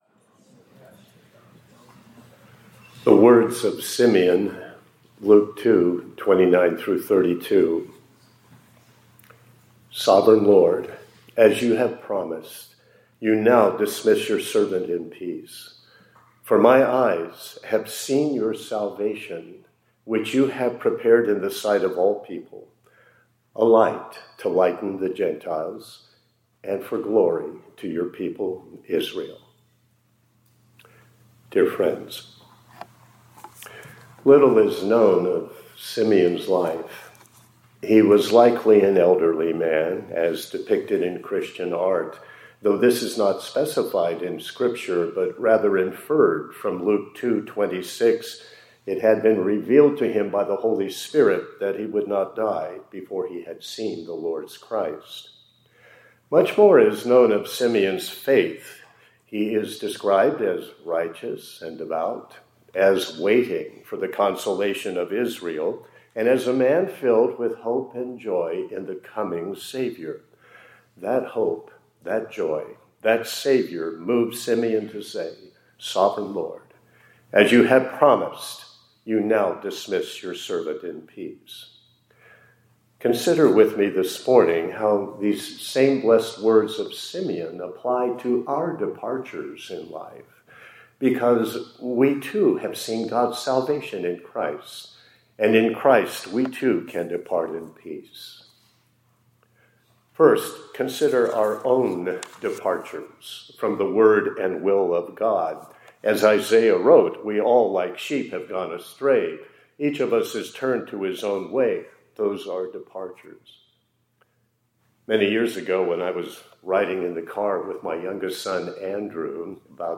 2026-02-16 ILC Chapel — Depart in Peace